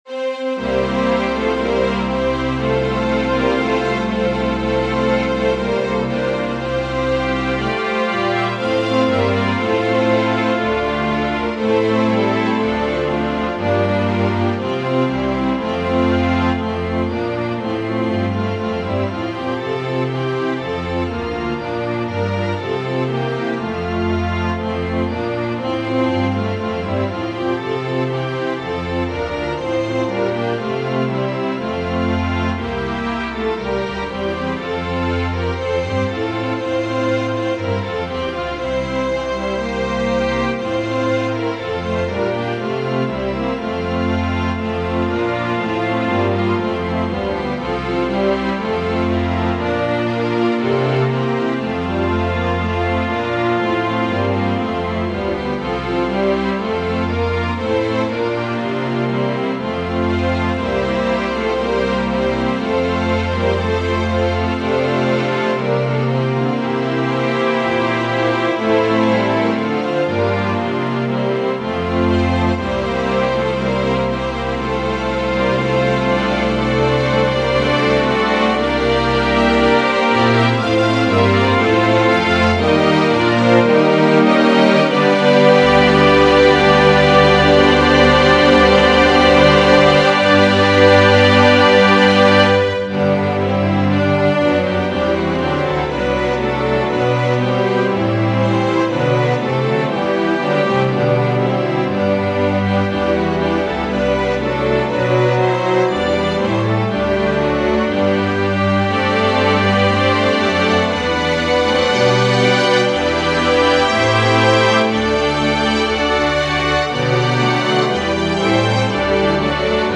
Organ/Organ Accompaniment